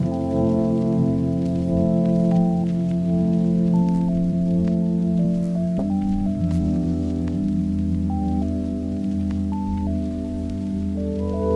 罗德斯洛菲
Tag: 83 bpm Soul Loops Synth Loops 1.95 MB wav Key : Unknown